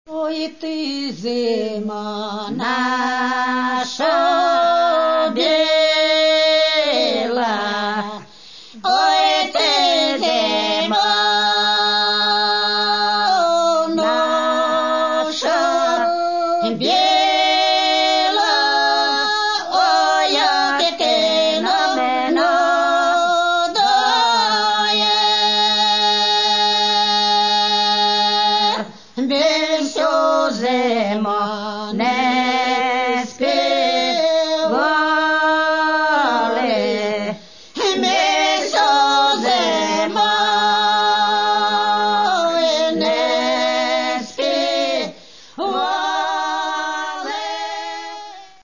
Каталог -> Народная -> Аутентичное исполнение
веснянка